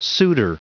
Prononciation du mot suitor en anglais (fichier audio)
Prononciation du mot : suitor